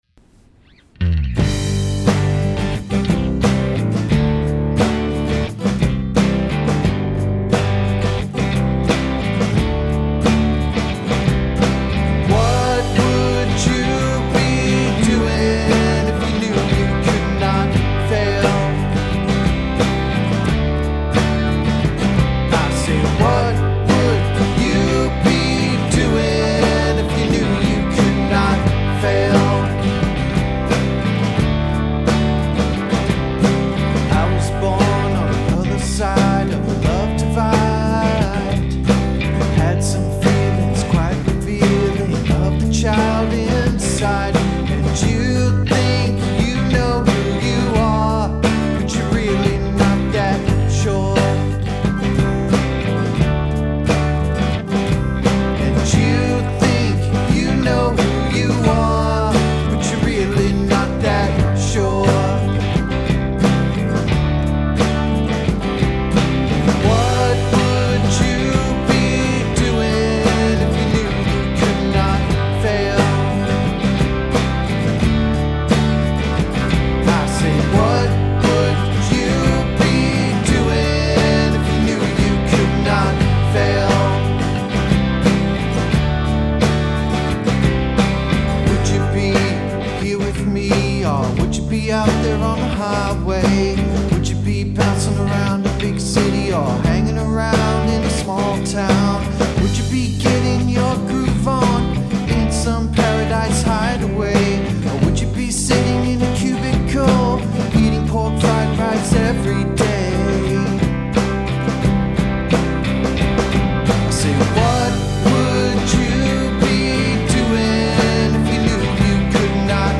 The song is really simple so I kept the production simple-no overdubs and I didn't go crazy with plugin s. I did run the mix through a compressor+tape sim on the master channel and through the vintage warmer after that so it might be a bit fuzzy. Drummers feel free to tell me how crappy the drums sound, i'm going through a crisis with the addictive drums plugin.